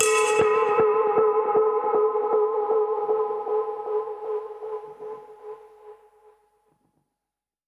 Index of /musicradar/dub-percussion-samples/125bpm
DPFX_PercHit_A_125-13.wav